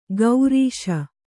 ♪ gaurīśa